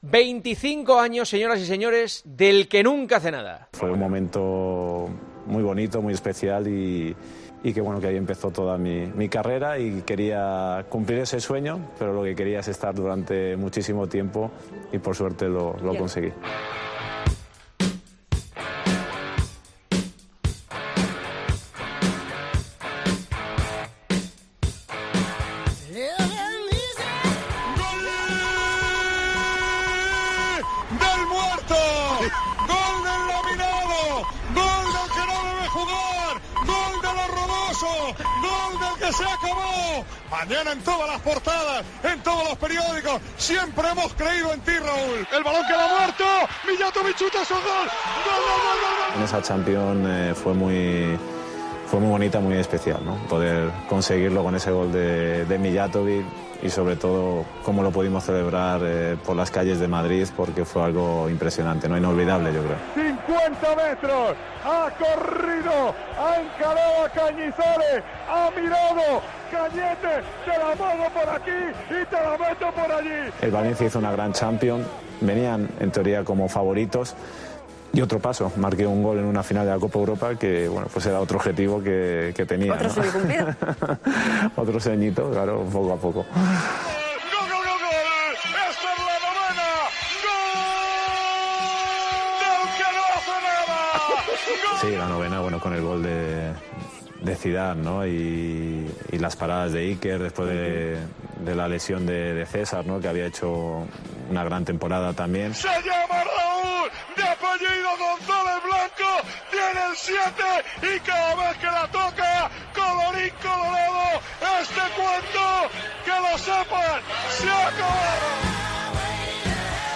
AUDIO: Escuchamos algunas de las narraciones históricas de Manolo Lama y nos preguntamos si Raúl estaría preparado para dirigir al Real Madrid.